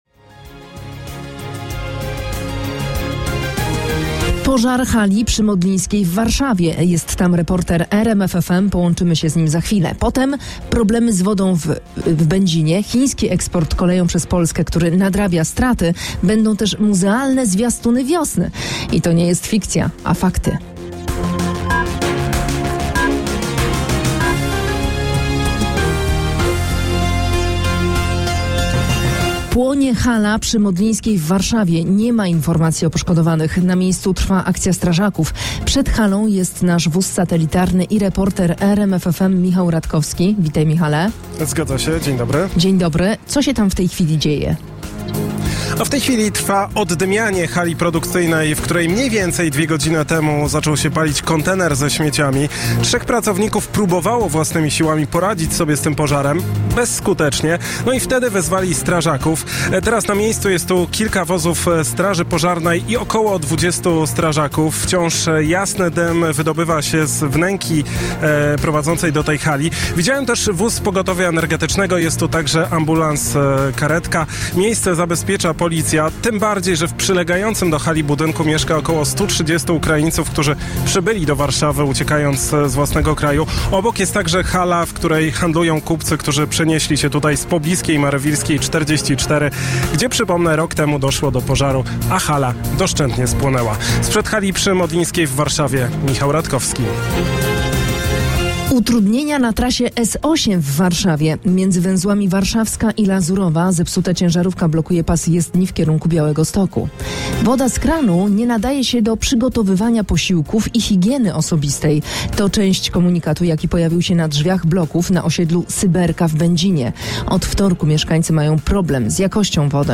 Najświeższe wiadomości z kraju i świata przygotowywane przez dziennikarzy i korespondentów RMF FM. Polityka, społeczeństwo, sport, kultura, ekonomia i nauka. Relacje na żywo z najważniejszych wydarzeń.